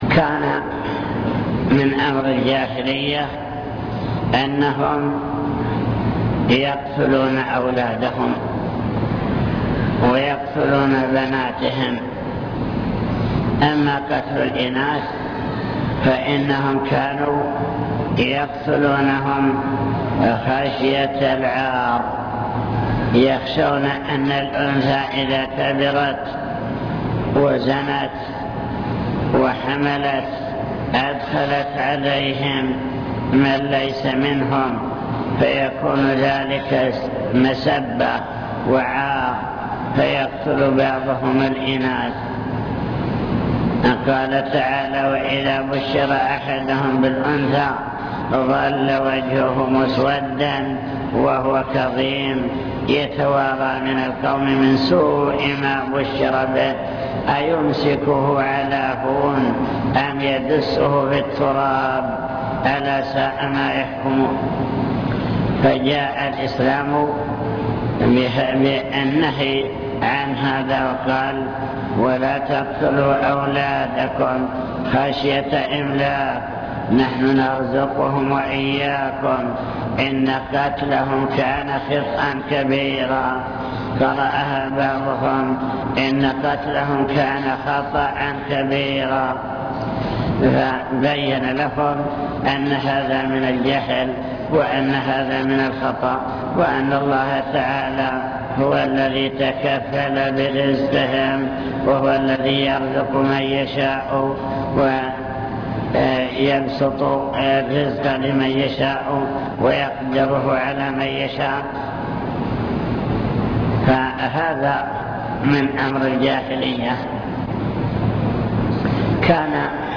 المكتبة الصوتية  تسجيلات - محاضرات ودروس  محاضرة حول توجيهات في العقيدة والأسرة